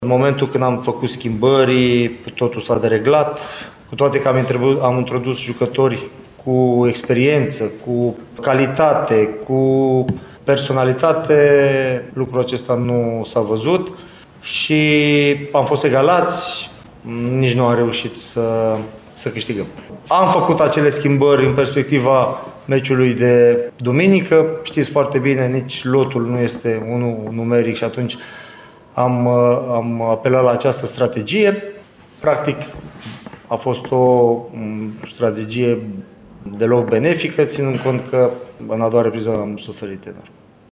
La arădeni, schimbările făcute nu s-au dovedit mai eficiente, ba din contră, așa cum a spus la final de meci însuși antrenorul Adrian Mihalcea: